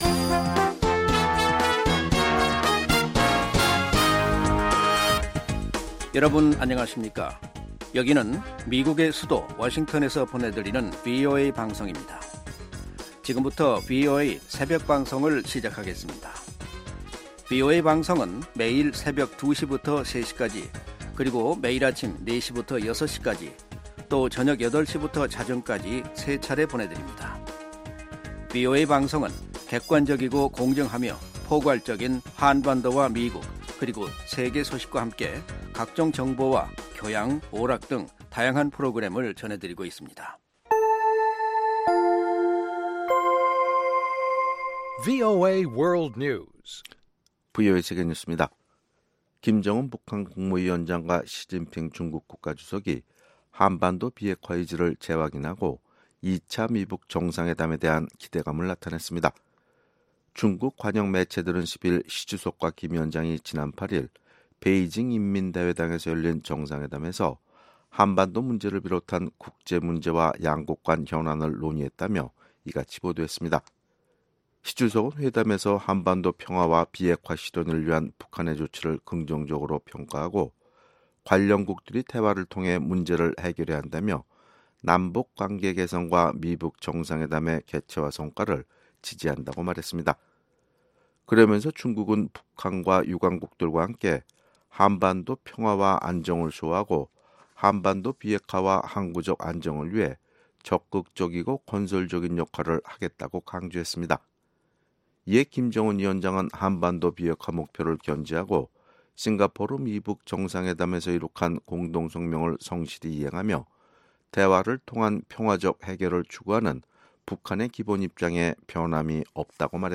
VOA 한국어 '출발 뉴스 쇼', 2019년 1월 11일 방송입니다. 김정은 북한 국무위원장과 시진핑 중국 국가주석은 베이징 회담에서 2차 미-북 정상회담에 대한 기대감을 나타냈습니다. 문재인 한국 대통령은 신년 기자회견에서 김정은 위원장의 중국 방문을 긍정적으로 평가하고, 2차 미-북 정상회담이 임박했음을 내비쳤습니다.